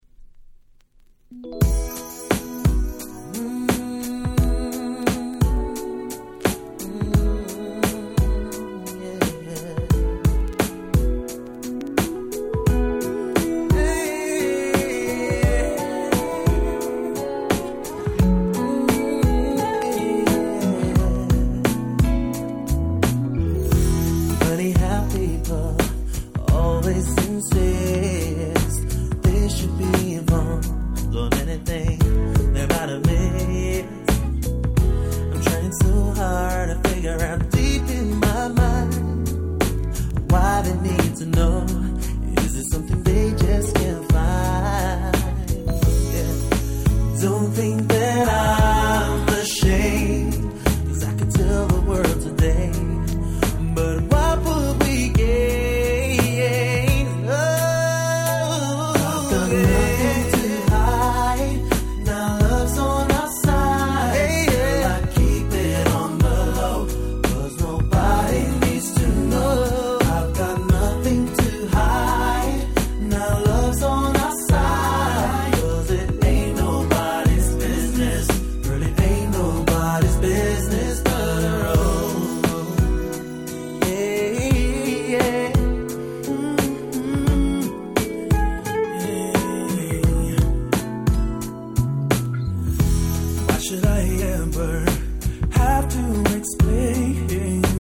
98' Nice UK Mid R&B !!